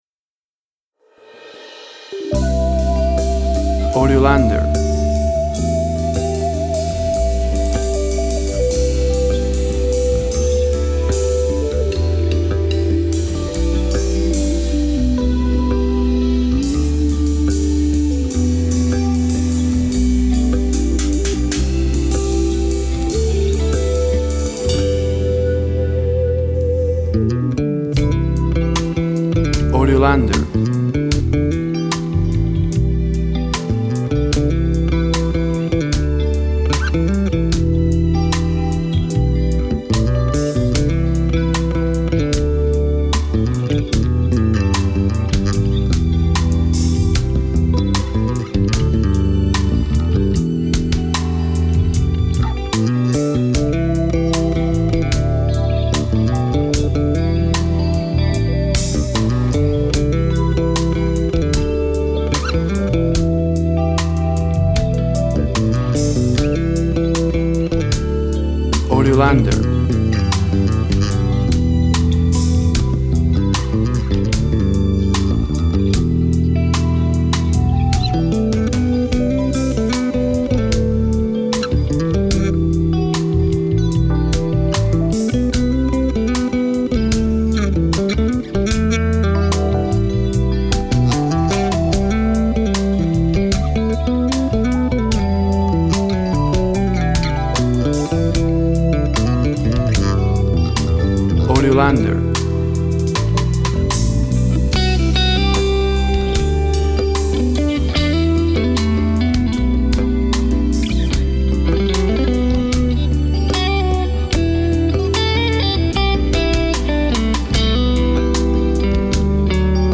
Instrumental ambient pop ballad.
Tempo (BPM) 75